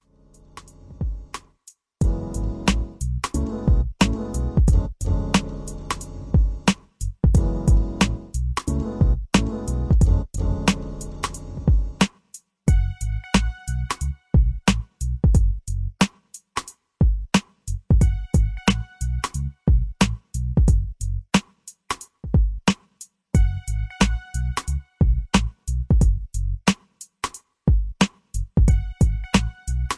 Grimey East Coast HipHop